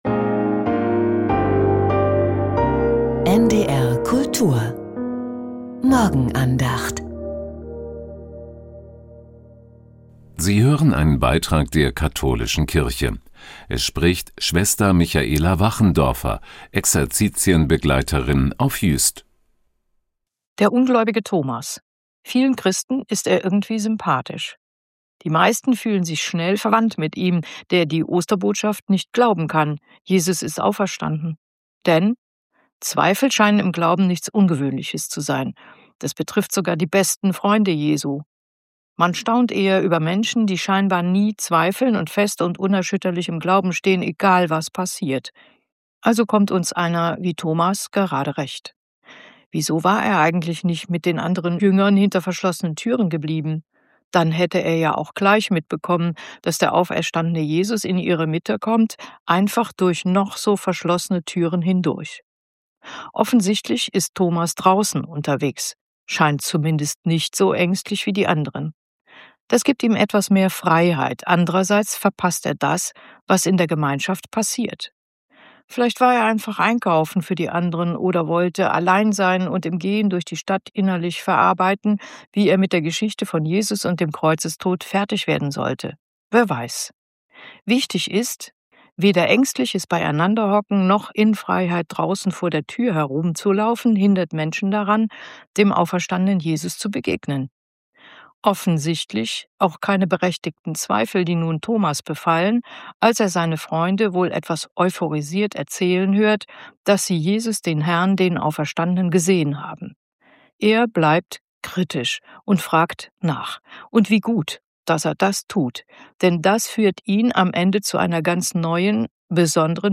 Die Morgenandacht bei NDR Kultur